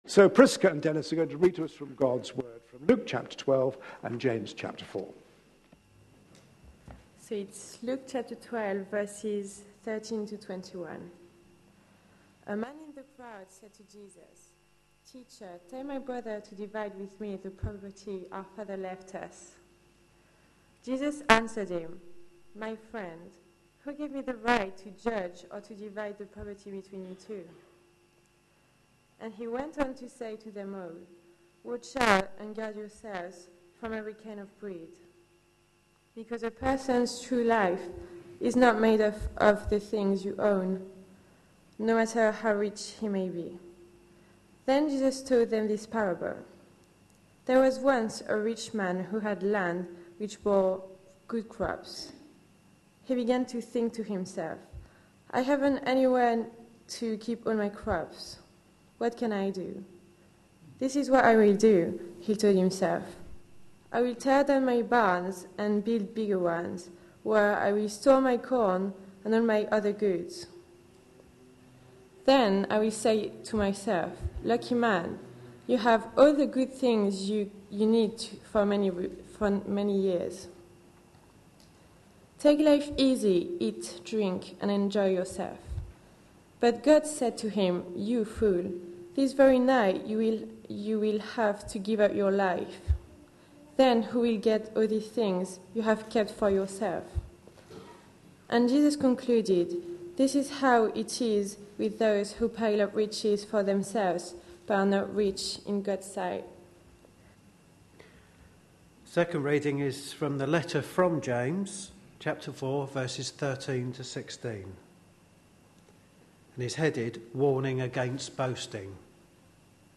A sermon preached on 29th September, 2013, as part of our Objections to faith answered! series.